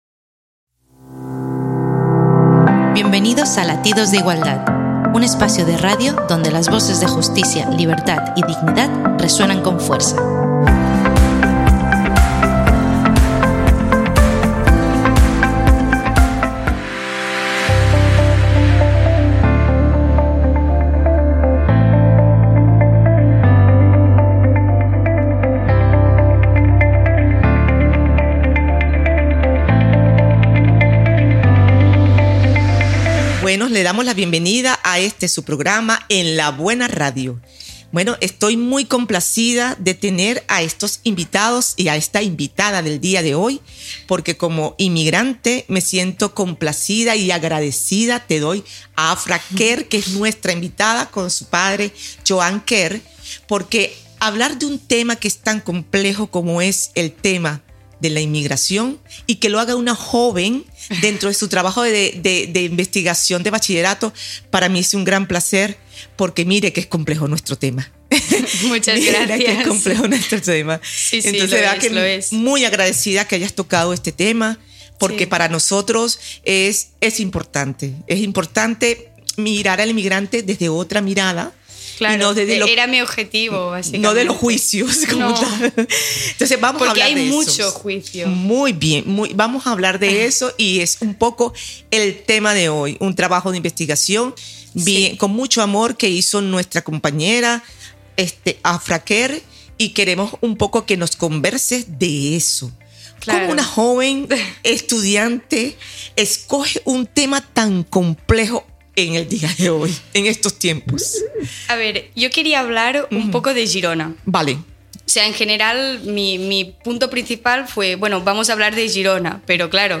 Entrevista Drets Humans i inmigració.